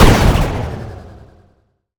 STT_Explosion_Sound.wav